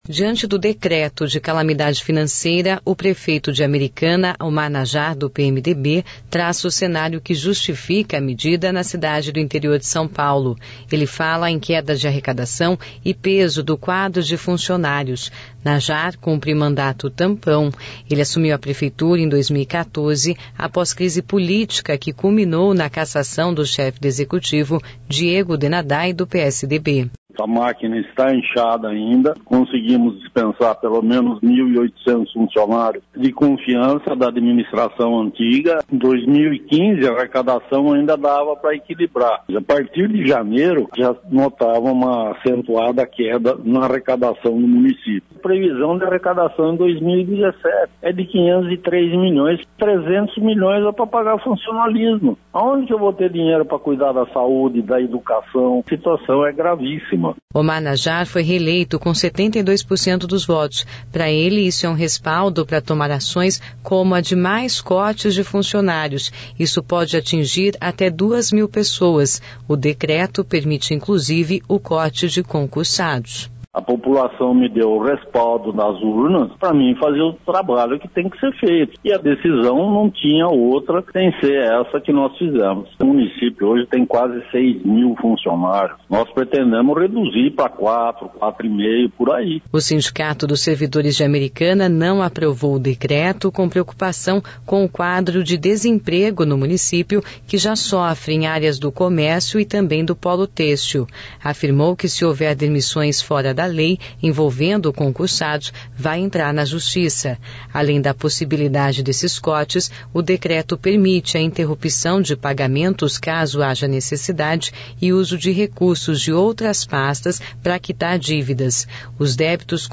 Diante do decreto de calamidade financeira, o prefeito de Americana, Omar Najar do PMDB, traça o cenário que justifica a medida – ele fala em queda na arrecadação e o peso do quadro de funcionários, na cidade do interior de São Paulo.